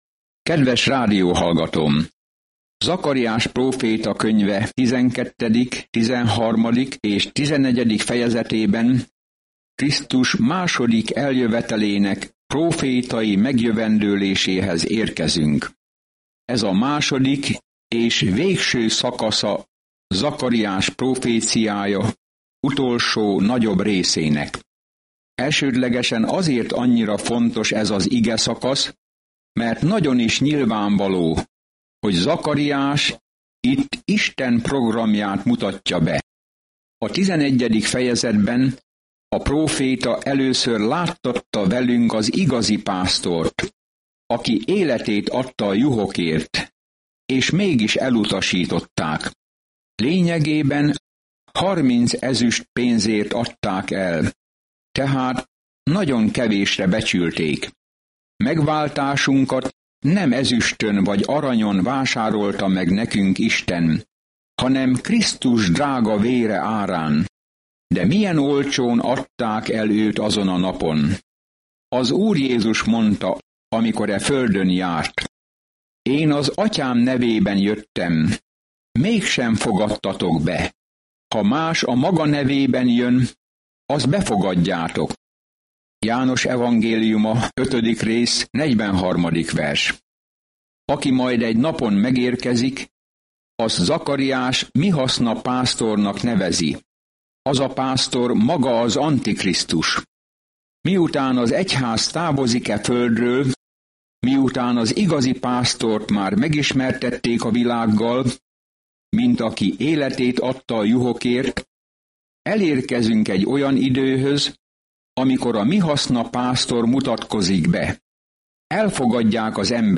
Szentírás Zakariás 12:1 Nap 24 Terv elkezdése Nap 26 A tervről Zakariás próféta látomásokat oszt meg Isten ígéreteiről, hogy reményt adjon az embereknek a jövőre nézve, és arra buzdítja őket, hogy térjenek vissza Istenhez. Napi utazás Zakariáson keresztül, miközben hallgatod a hangos tanulmányt, és olvasol válogatott verseket Isten szavából.